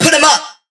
VR_vox_hit_putemup.wav